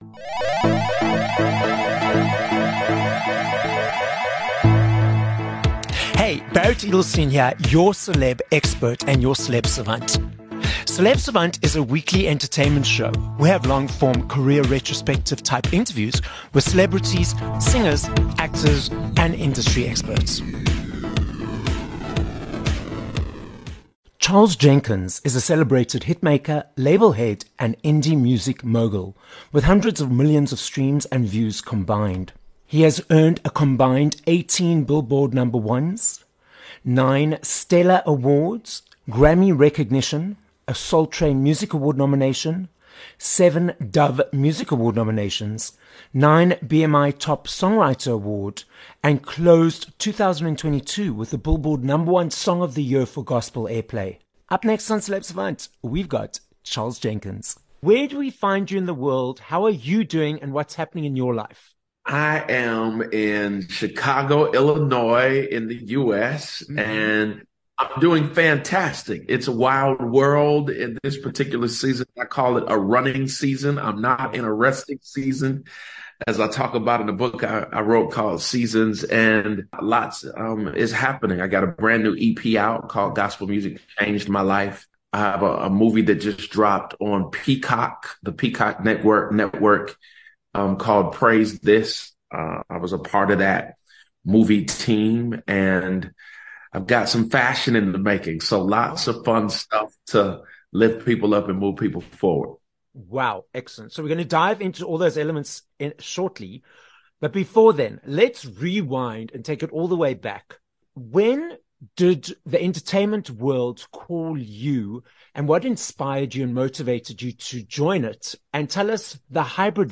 4 Jun Interview with Charles Jenkins